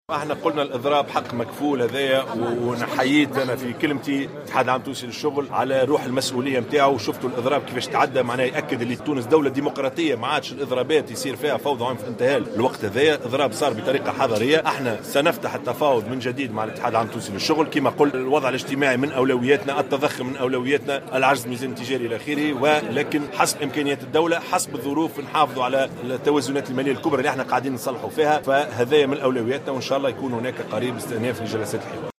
قال رئيس الحكومة يوسف الشاهد في تصريح لمراسل الجوهرة "اف ام" اليوم الثلاثاء على هامش افتتاح فعاليات الجلسة التأسيسية للمجلس الوطني للحوار الاجتماعي إن الإضراب حق مكفول بالدستور .